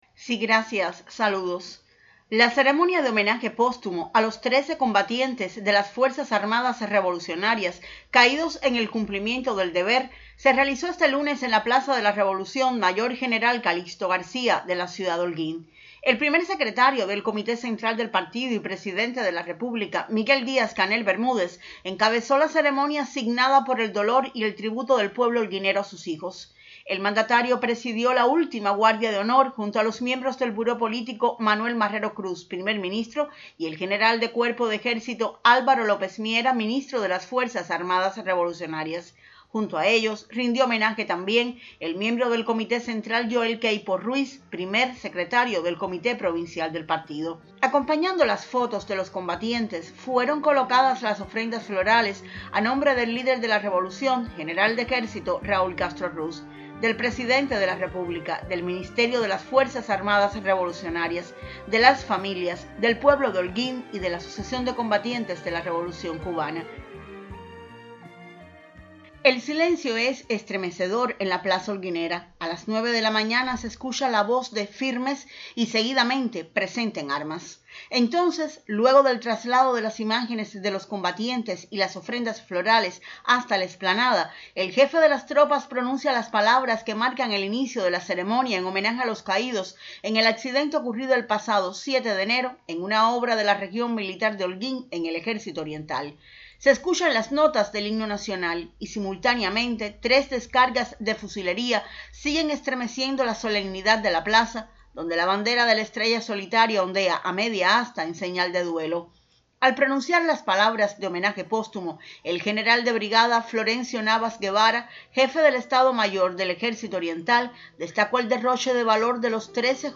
En representación de Cuba toda, el pueblo holguinero rindió tributo a los 13 combatientes de las FAR caídos en el cumplimiento del deber. El Presidente Díaz-Canel encabezó la ceremonia de homenaje póstumo
ceremonia__holguin_esteeee.mp3